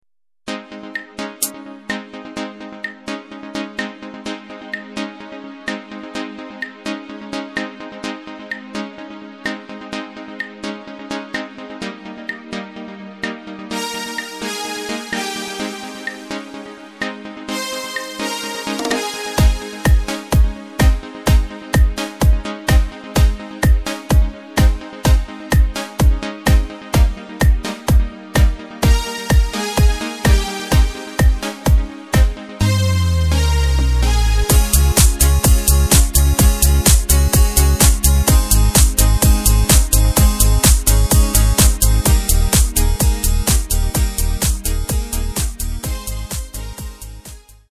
Takt:          4/4
Tempo:         127.00
Tonart:            Ab
Discofox aus dem Jahr 2022!
Playback mp3 Mit Drums